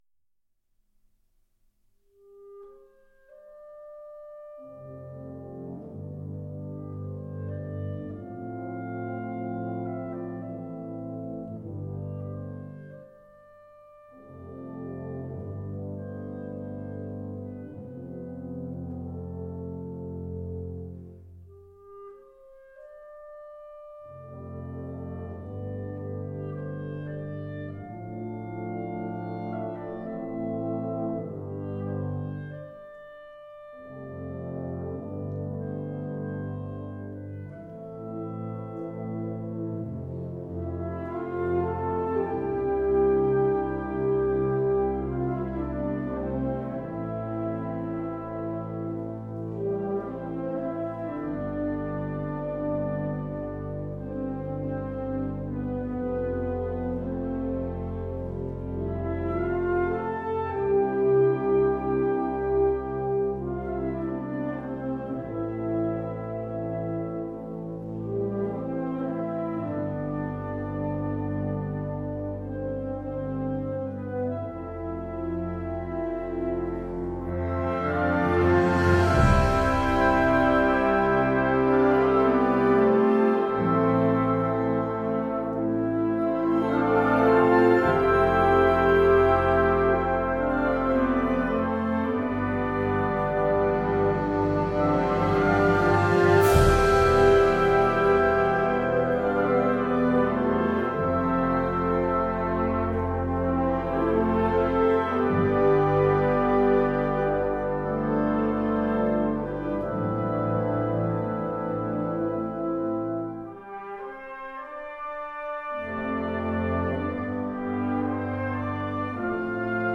Gattung: für Blasorchester
Besetzung: Blasorchester